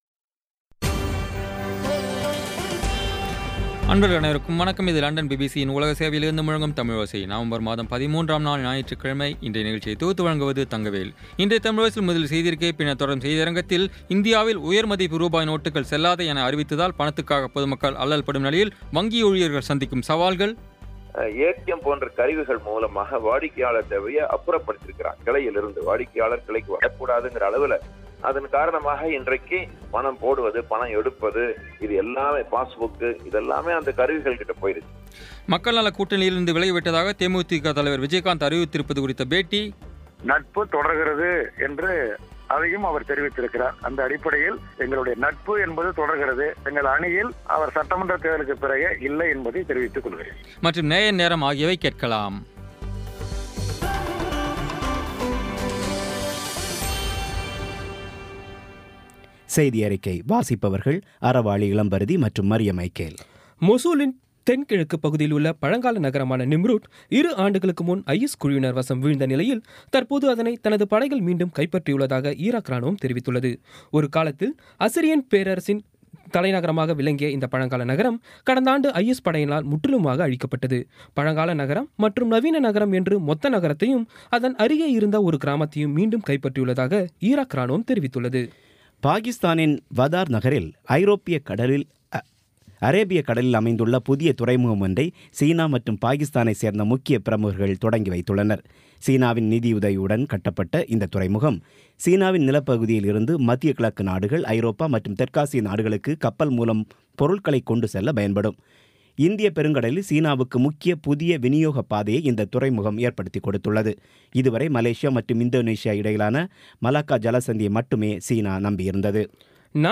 இன்றைய தமிழோசையில், இந்தியாவில், 500 மற்றும் ஆயிரம் ரூபாய் நோட்டுக்கள் செல்லாது என அறிவித்ததால், பணத்துக்காக பொதுமக்கள் அல்லல்படும் நிலையில், வங்கி ஊழியர்கள் சந்திக்கும் சவால்கள் குறித்த ஆய்வு மக்கள் நலக்கூட்டணியில் இருந்து விலகிவிட்டதாக தேமுதிக தலைவர் விஜயகாந்த் அறிவித்திருப்பது குறித்த பேட்டி மற்றும் நேயர் நேரம் ஆகியவை கேட்கலாம்.